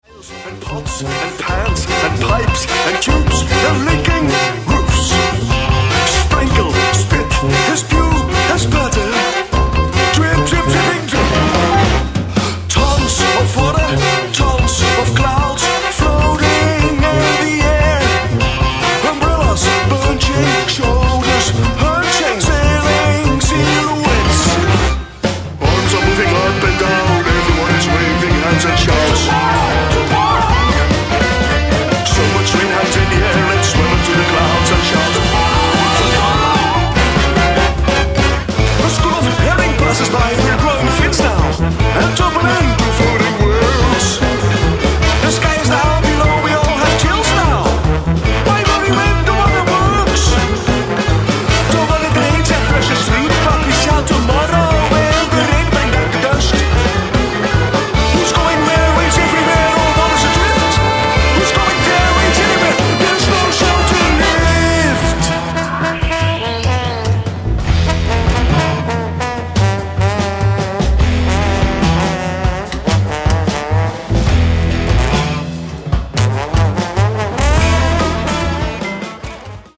アヴァンギャルド・ロックとビッグバンド・ジャズが融合、物凄いインパクトとパワー
ビッグバンド・ジャズとアヴァンロックを融合したパワフルで過激なサウンドが展開する。
Accordion, Keyboards
Baritone Saxophone, Flute
Guitar
Tuba